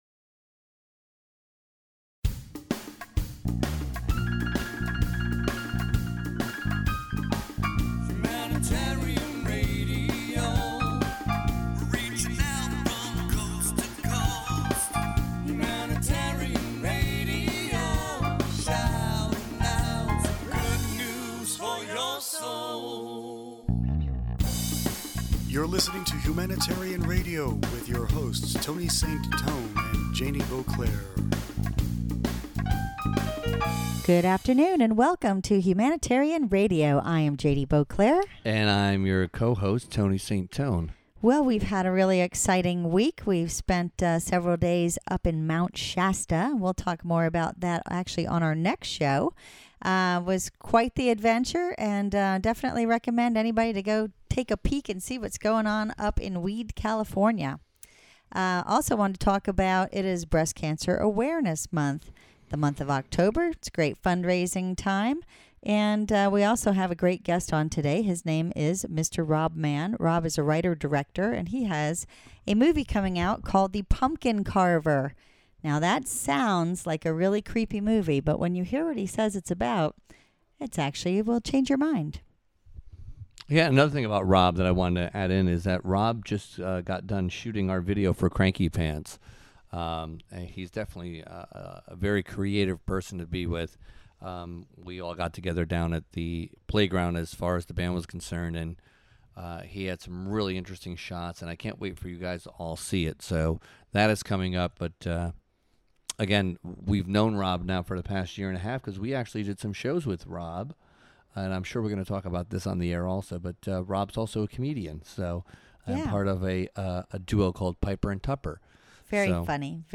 Entertainment HR Interview